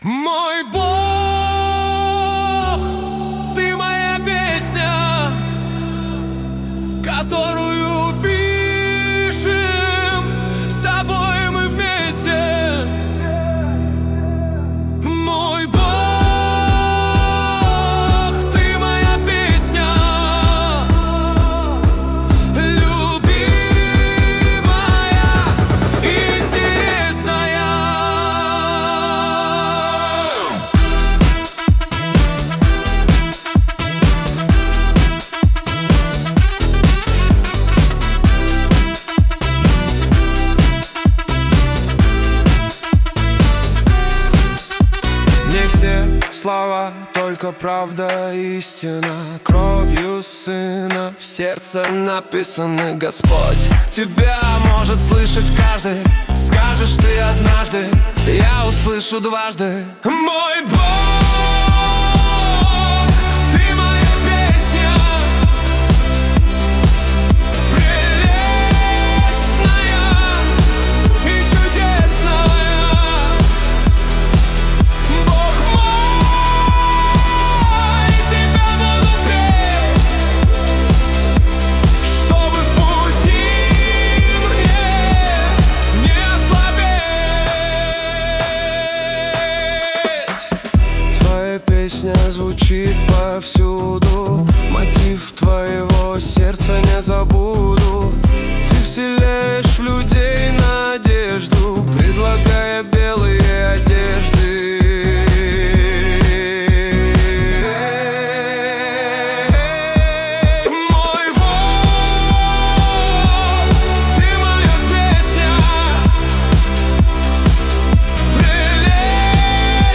песня ai
141 просмотр 624 прослушивания 72 скачивания BPM: 129